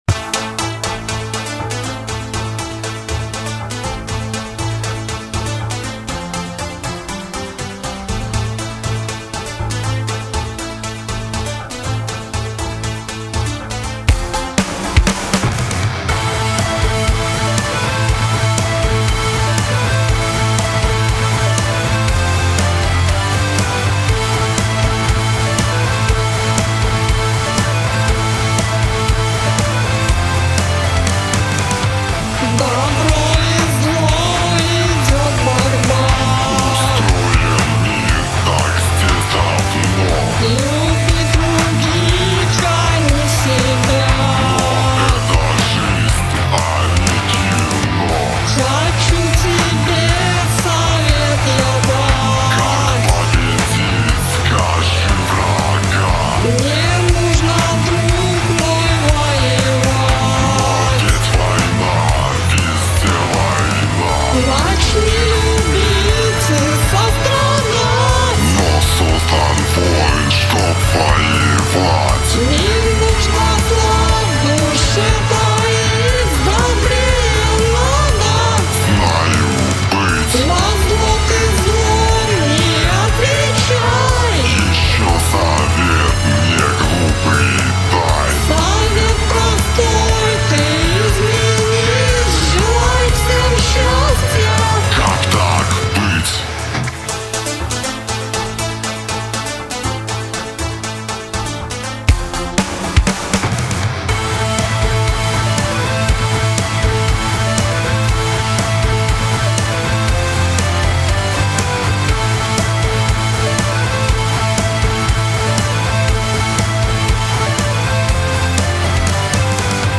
Записали песню. Покритикуйте, пожалуйста, что со звуком гитар, да и в целом?
Звучит плохо. Голос вообще искуственный.